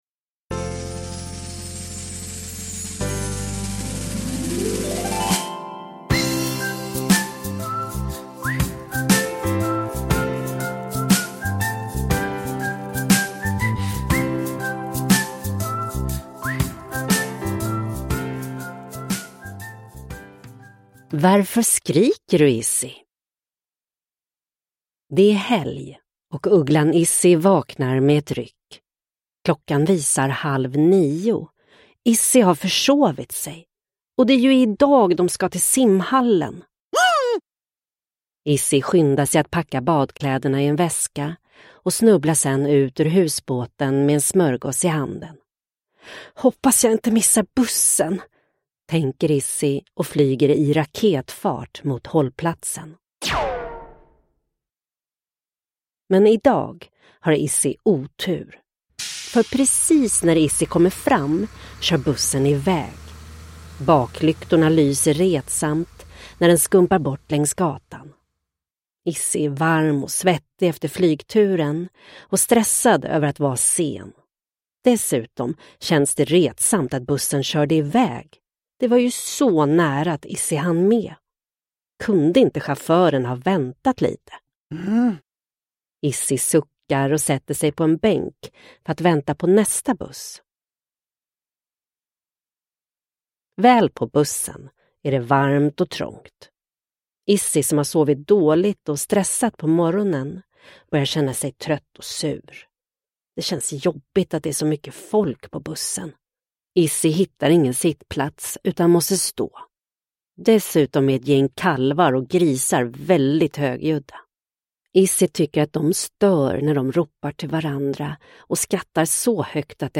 Varför skriker du, Izzy? – Ljudbok – Laddas ner